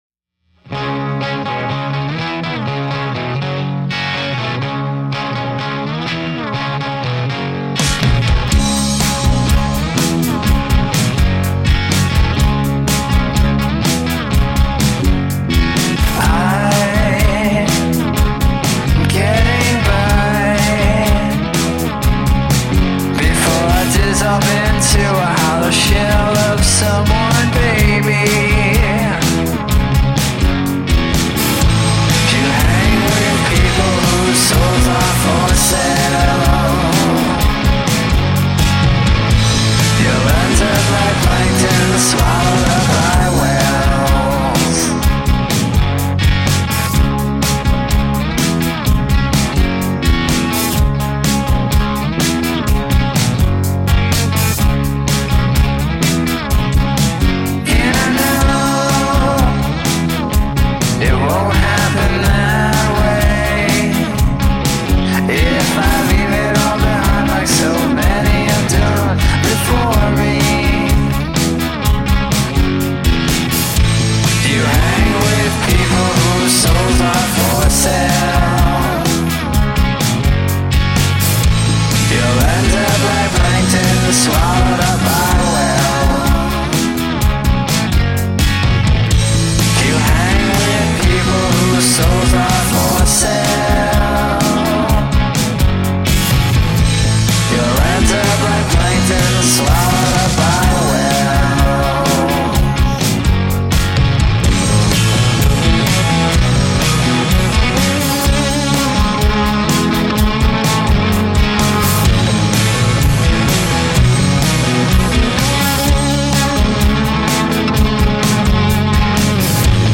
Guitars/Vocals
Drums/Percussion
Bass